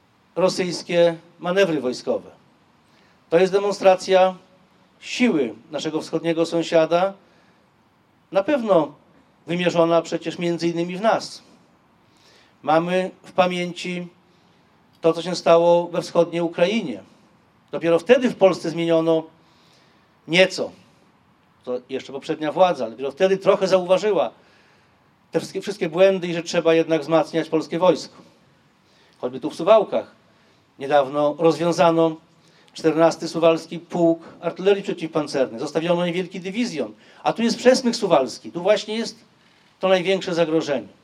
Po mszy świętej w miejscowej konkatedrze i złożeniu kwiatów pod pomnikiem Marszałka Józefa Piłsudskiego głos pod Dąbkiem Wolności zabrał poseł Jarosław Zieliński, sekretarz stanu w Ministerstwie Spraw Wewnętrznych i Administracji.